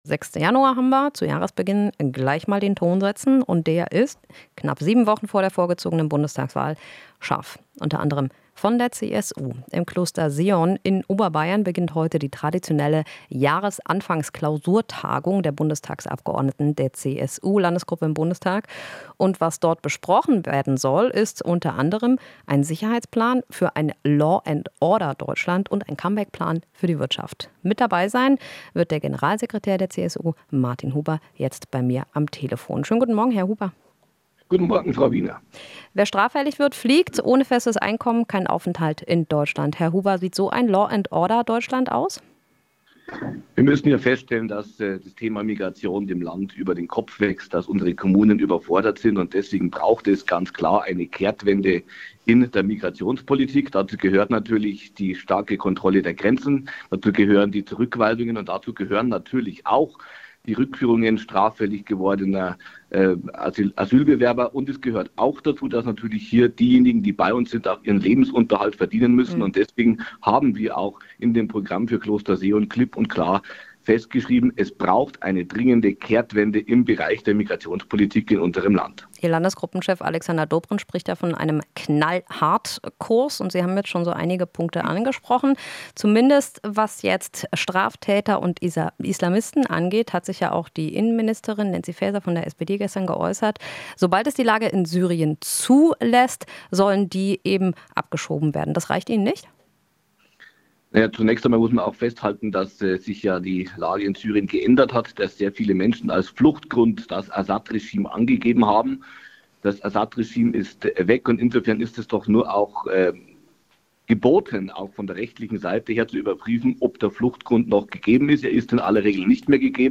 Interview - Huber (CSU): "Brauchen Kehrtwende in der Migrationspolitik"